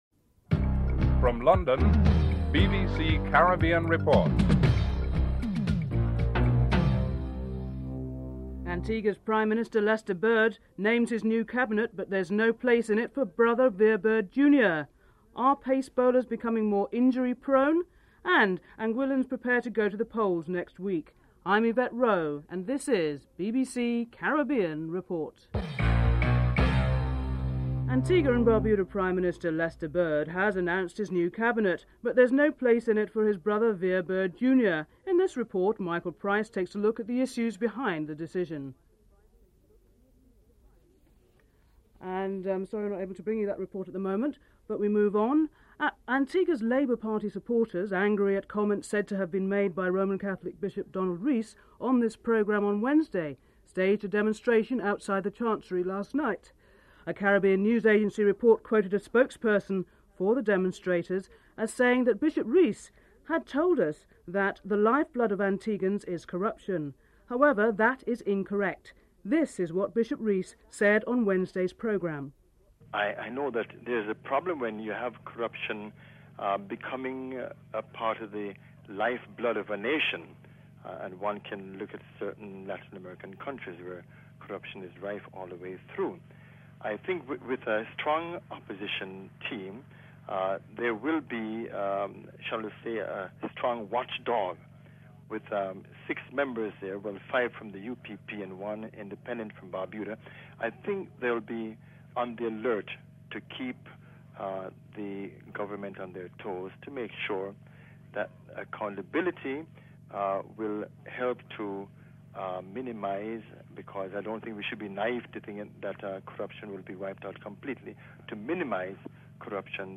9. Recap and theme music (14:22-15:02)